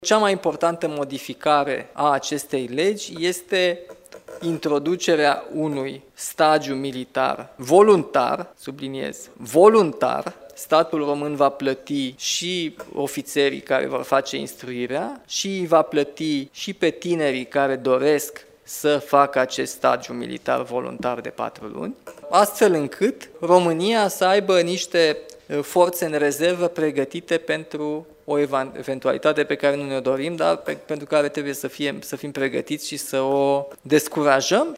Președintele României, Nicușor Dan: „Statul român va plăti și ofițerii care vor face instruirea și îi va plăti și pe tinerii care doresc să facă acest stagiu”